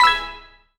collect_coin_04.wav